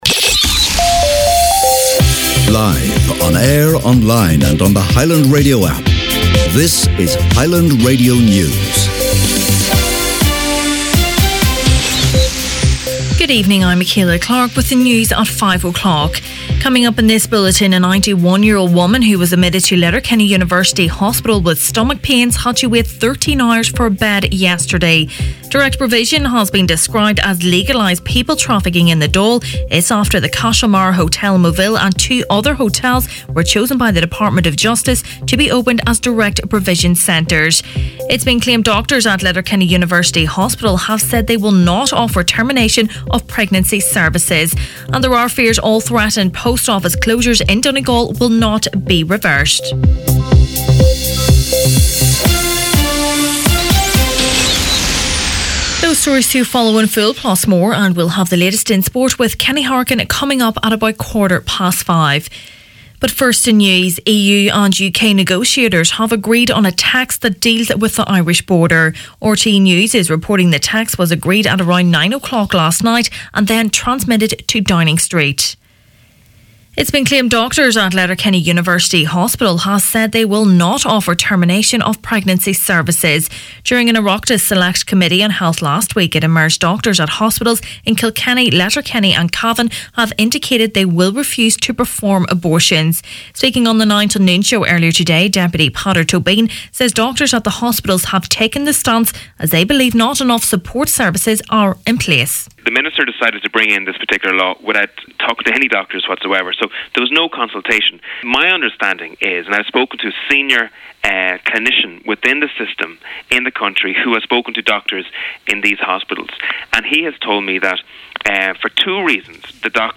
Main Evening News, Sport and Obituaries Tuesday November 13th